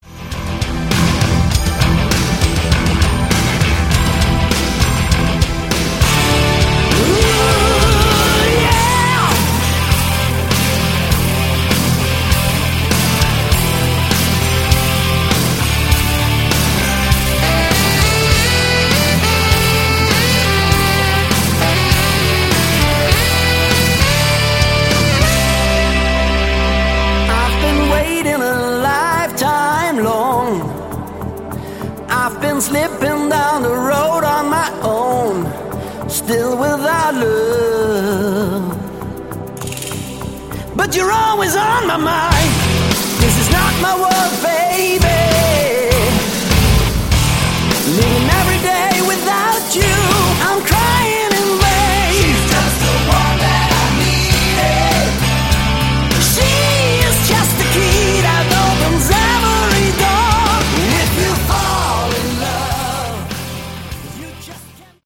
Category: Melodic Rock
Vocals
Keyboards
Sax
Guitars
Drums
Bass